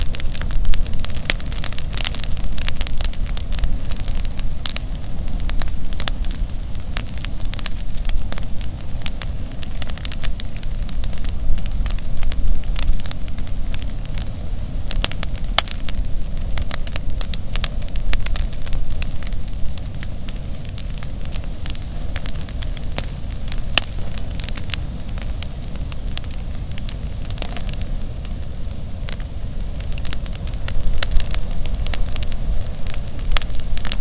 bonfire audio loop
bonfire.ogg